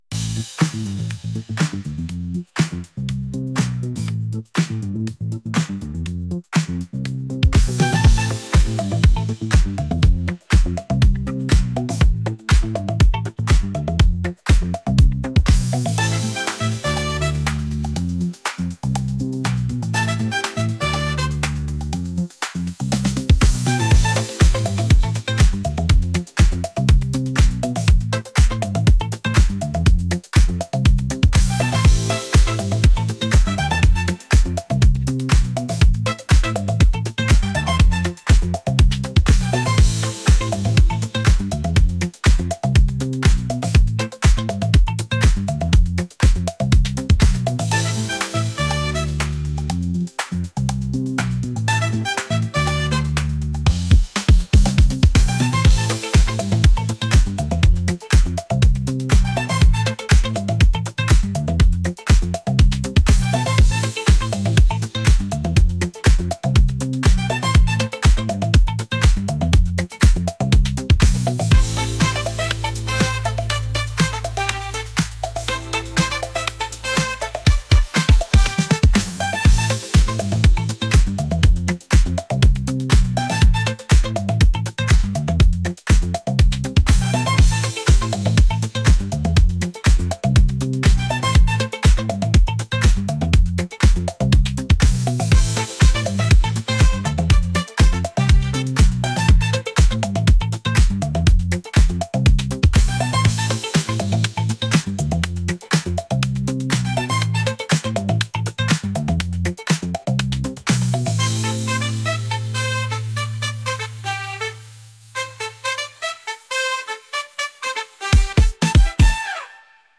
funky | groovy